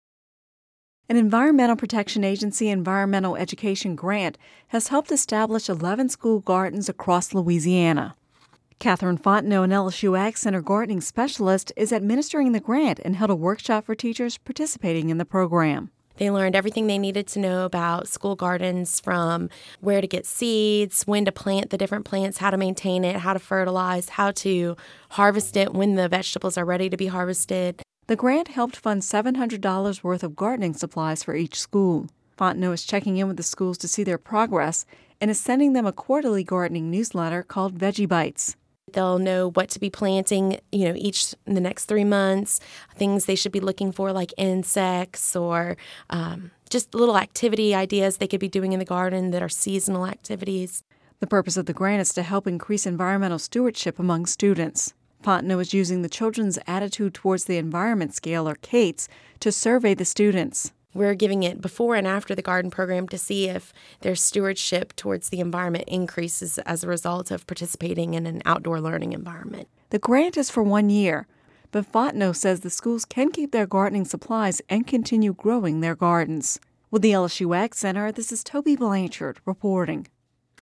(Radio News 11/29/10) An Environmental Protection Agency environmental education grant has helped establish 11 school gardens across Louisiana.